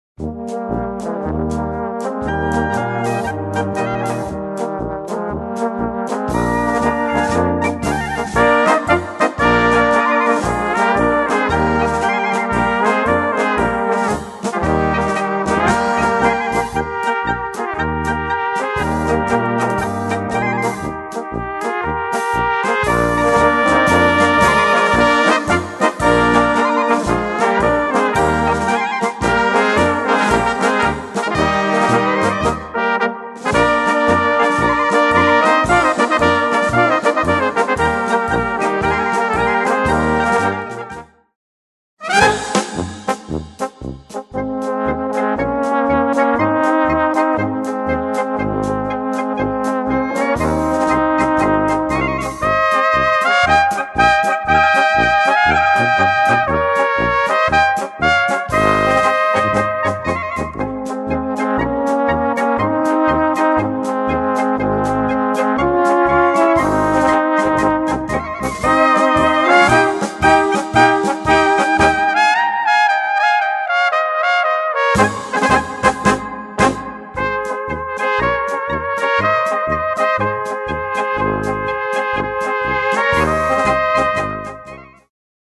Gattung: Polka für kleine Besetzung
Besetzung: Kleine Blasmusik-Besetzung
Wunderbare mährische Polka
Gesang
Schlagzeug